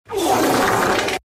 Fart Effect